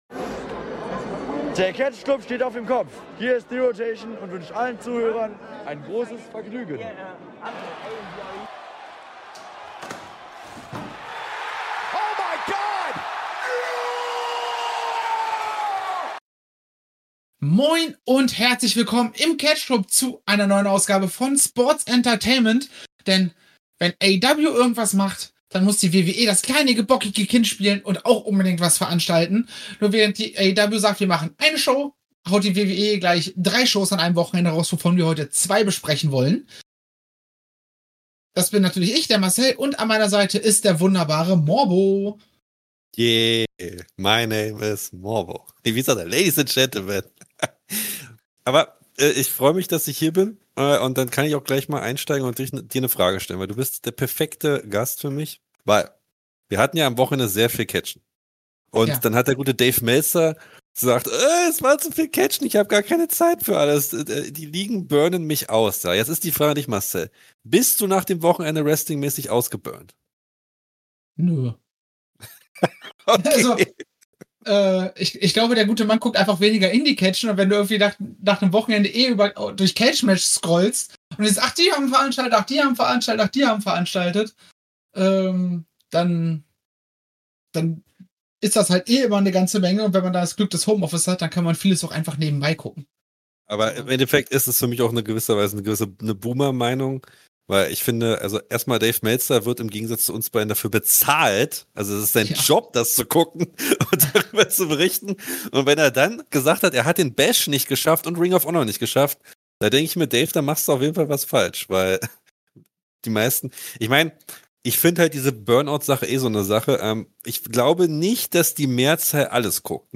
Am Ende ist, trotz aller technischen Widrigkeiten, diese schmackhafte Ausgabe von Sportsentertainment herausgekommen.